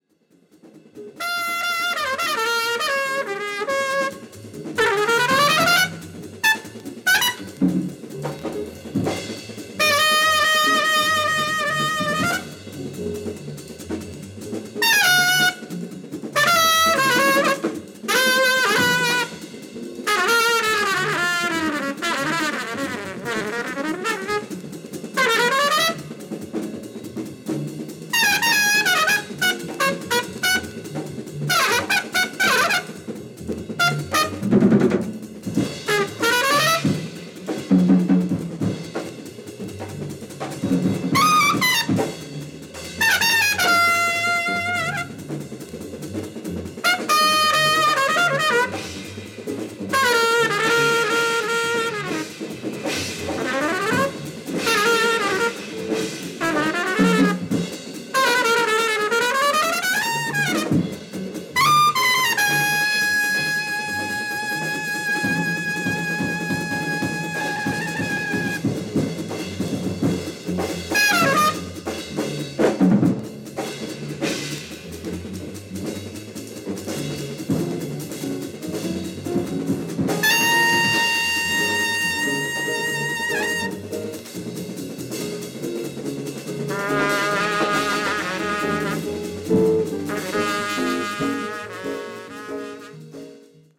Alto Saxophone
Drums